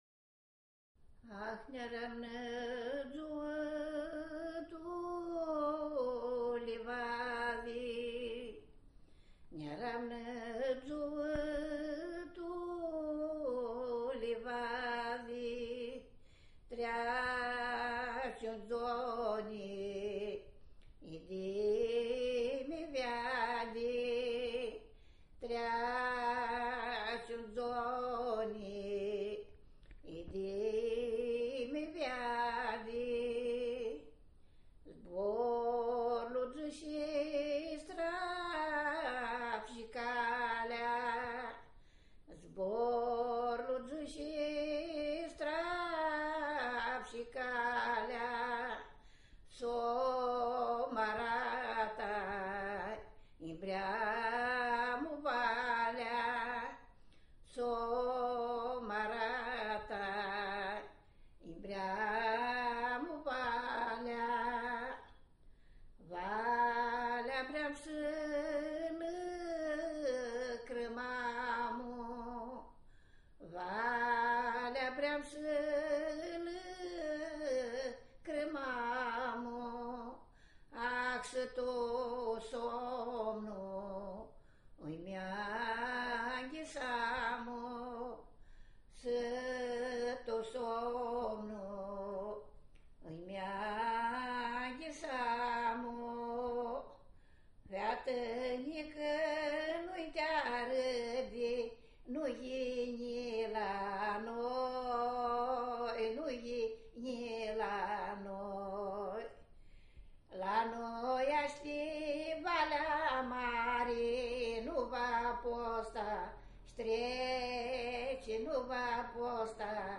Track 1 στο cd της επιτόπιας έρευνας).